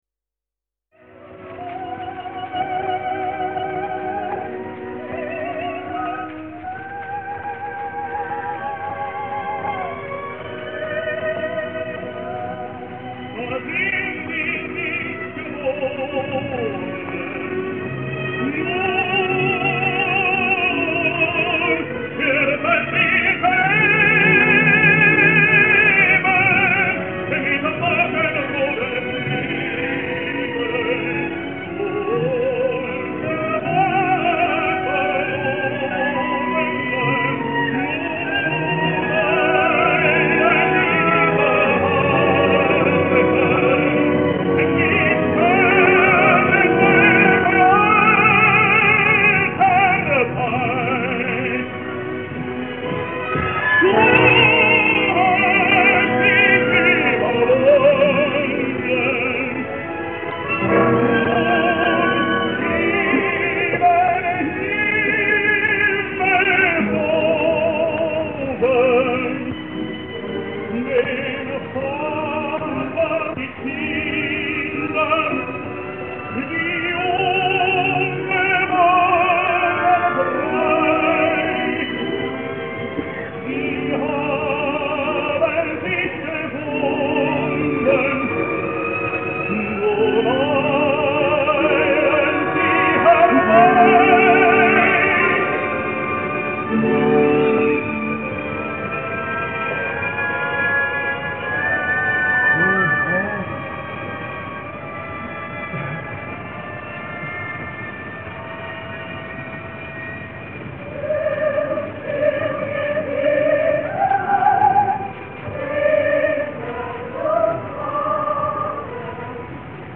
“Wenn das Herz aus Kristall”, atto III, (Franz Völker, 1933):